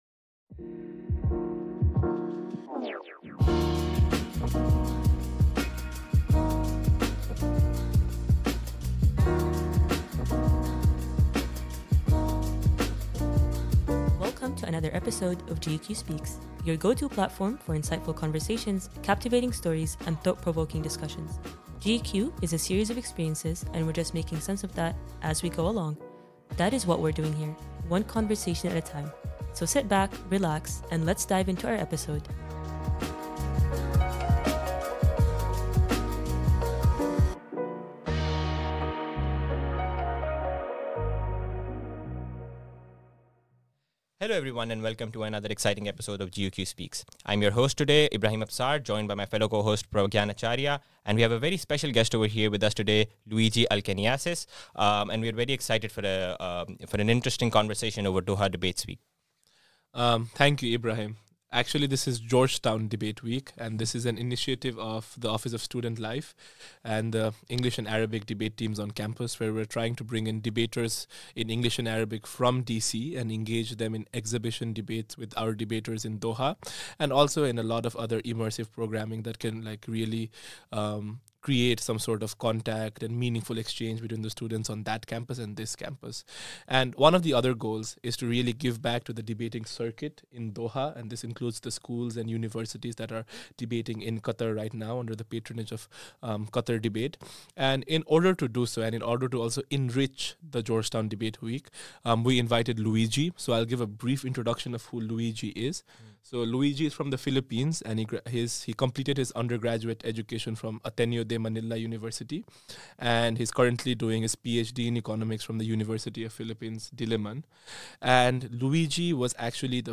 In this episode, podcast hosts discuss the experience of debating with a finalist of the 2023 World Universities Debating Championship.